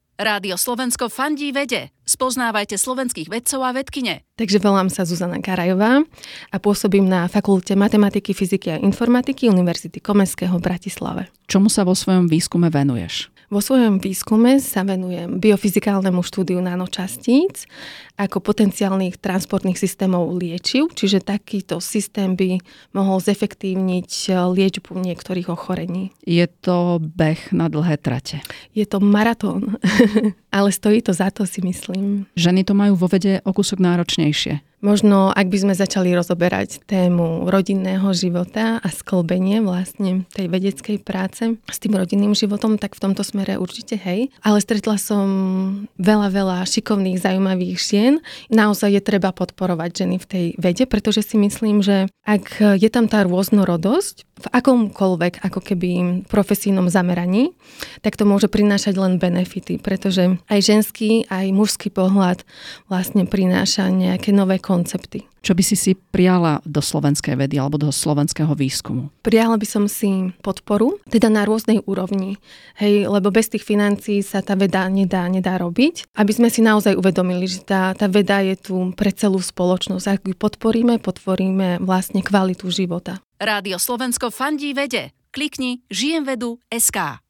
Žijemvedu v spolupráci s Rádiom Slovensko predstavuje slovenské vedkyne a vedcov v krátkych spotoch.
Všetky doterajšie rozhovory so súhlasom Rádia Slovensko priebežne uverejňujeme na našich stránkach.